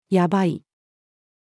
やばい-female.mp3